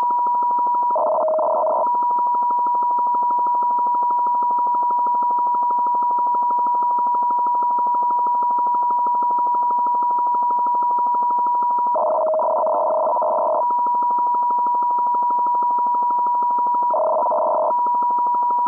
Сигнал FSK2 340 shift 200 baud